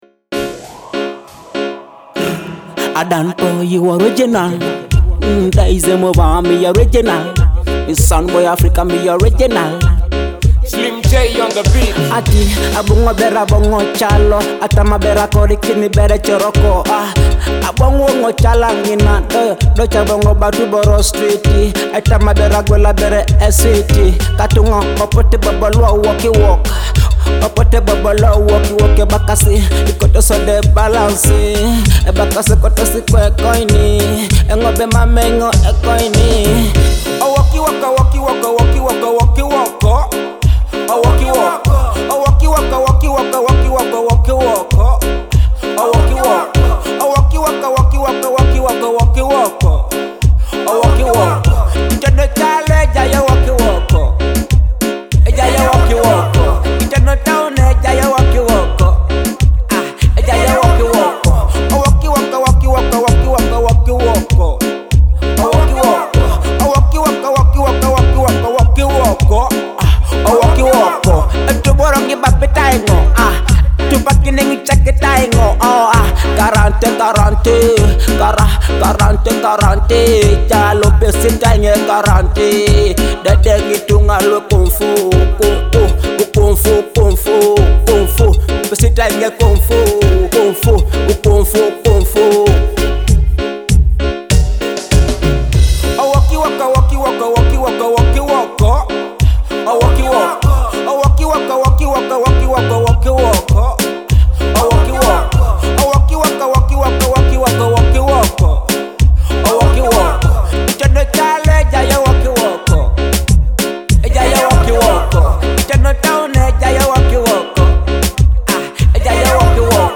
a gritty Teso dancehall track